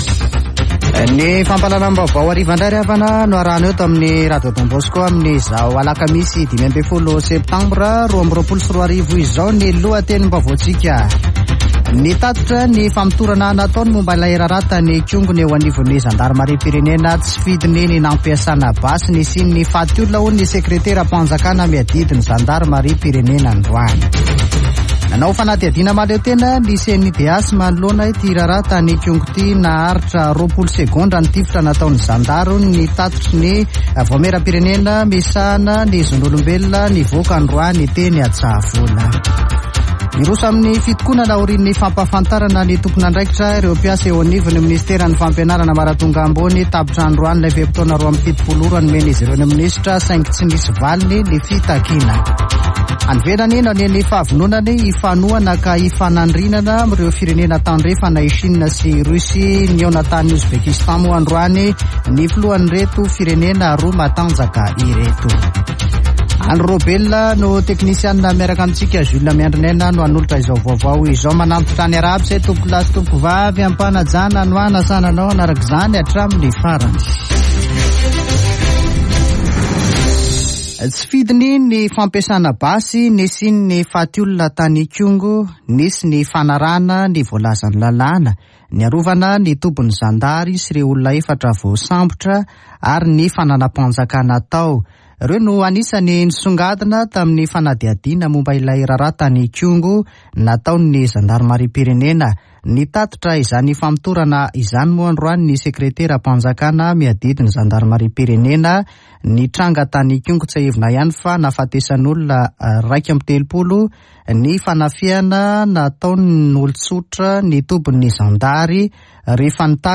[Vaovao hariva] Alakamisy 15 septambra 2022